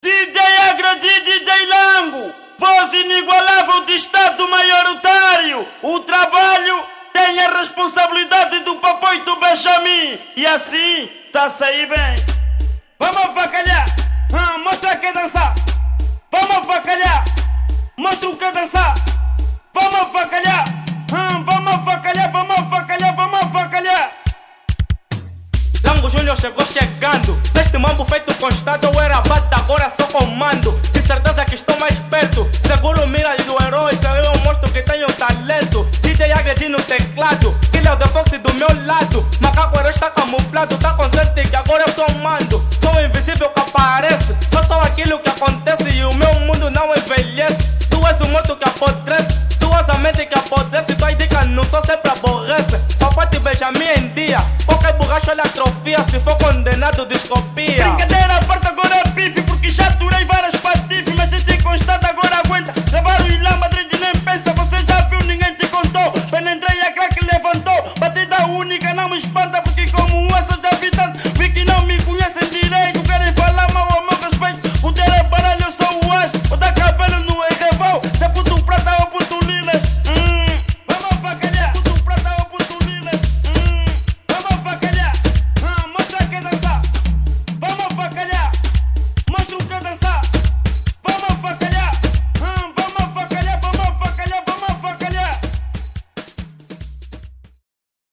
kuduro